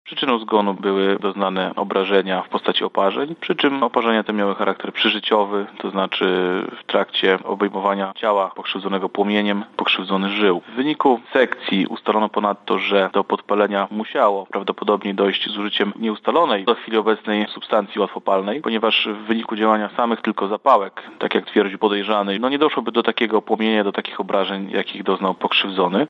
– tłumaczy Bartosz Wójcik, prokurator rejonowy